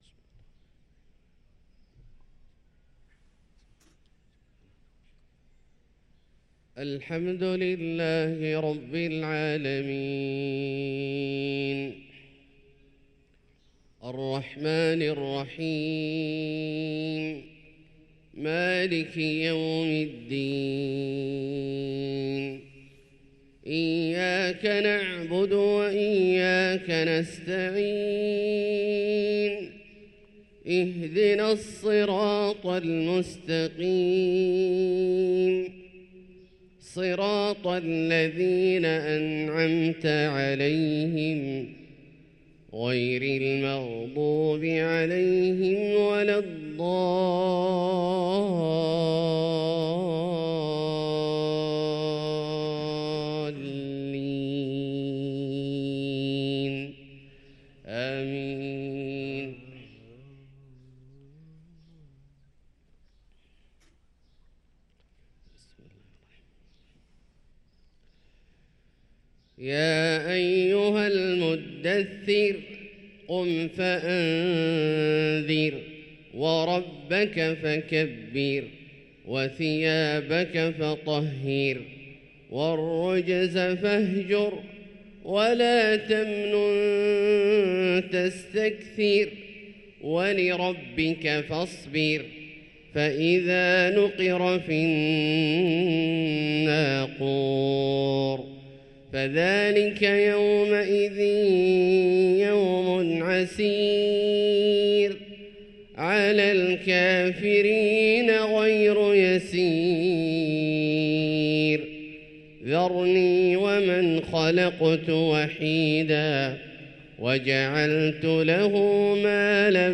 صلاة الفجر للقارئ عبدالله الجهني 6 ربيع الآخر 1445 هـ
تِلَاوَات الْحَرَمَيْن .